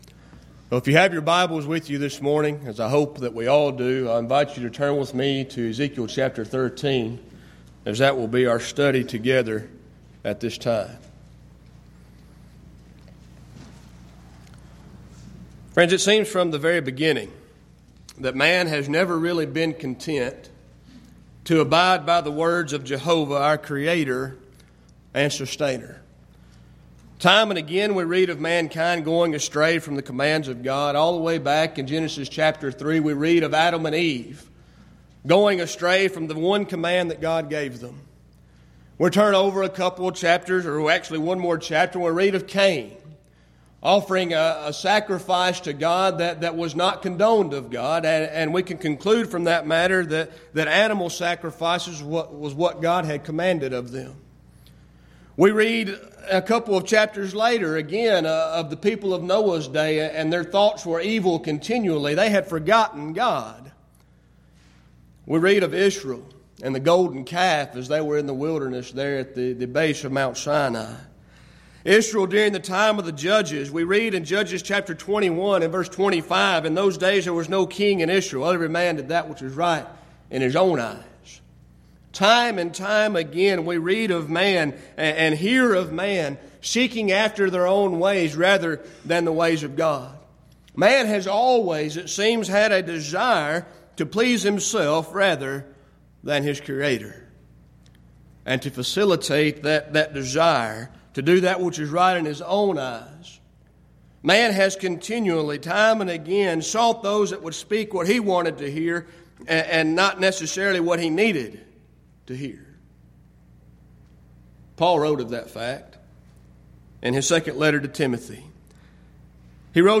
Event: 10th Annual Schertz Lectures Theme/Title: Studies in Ezekiel
lecture